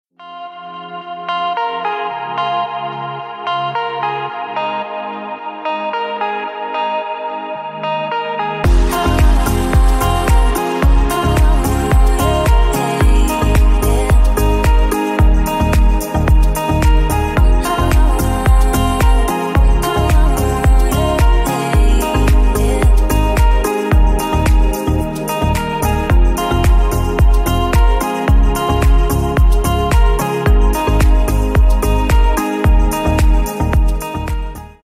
Клубные Рингтоны » # Спокойные И Тихие Рингтоны
Танцевальные Рингтоны